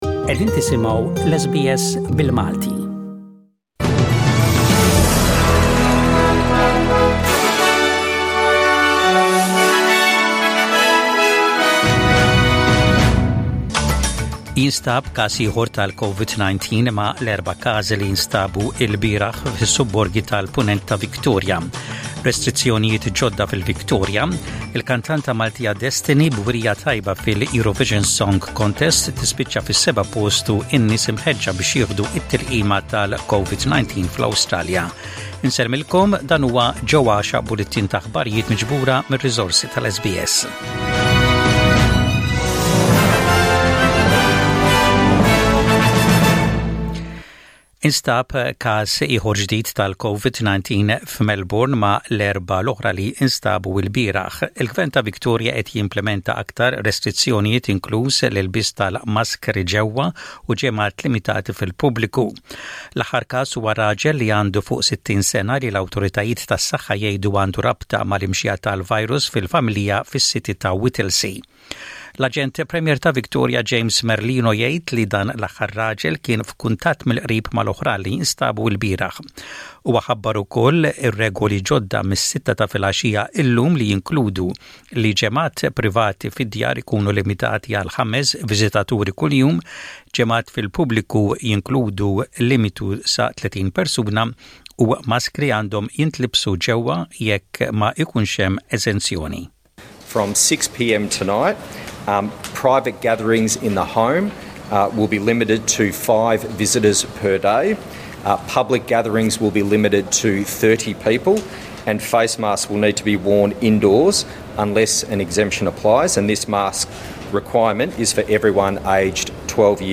Source: Maltese News - SBS Studio